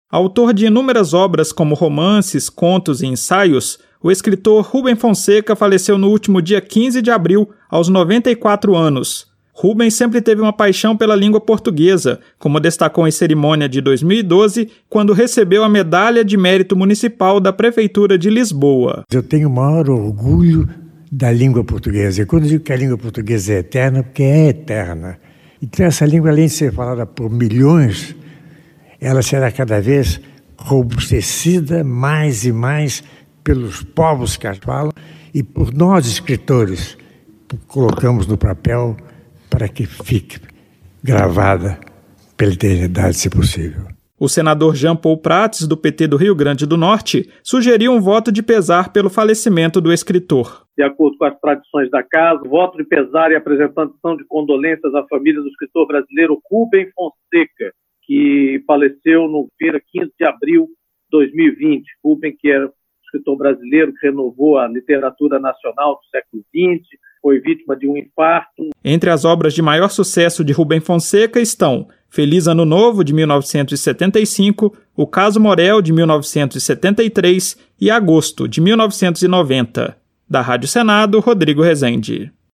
O senador Jean Paul Prates (PT-RN) sugeriu um voto de pesar em função do falecimento do escritor Rubem Fonseca. O ficcionista morreu aos 94 anos e deixou obras importantes na literatura nacional como “Feliz Ano Novo” e “Agosto”. A reportagem